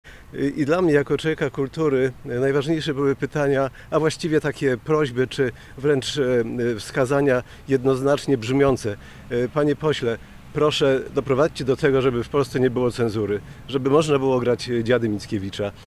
Konferencja prasowa odbyła się z udziałem wszystkich kandydatów i kandydatek KO.
-To są wybory o wszystko, mówił poseł Krzysztof Mieszkowski,